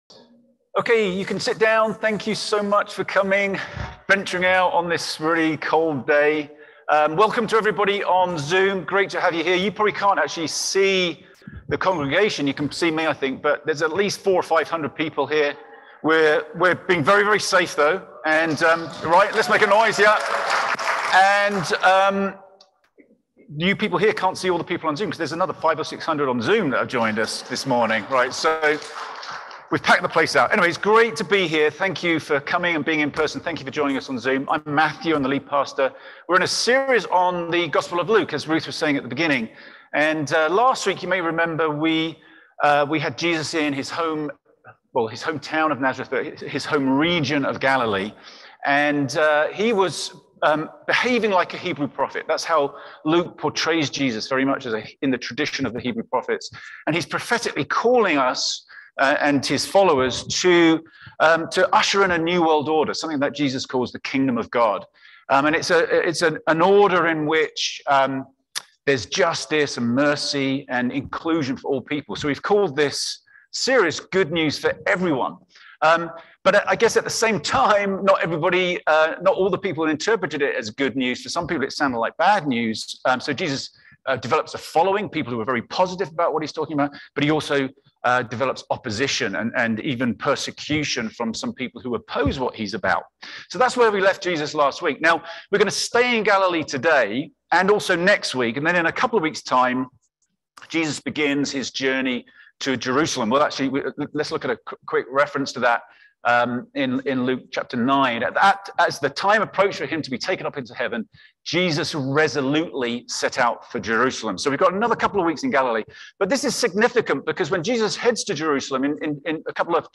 *Note: Apologies for the technical difficulties!